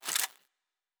Weapon 12 Foley 1 (Laser).wav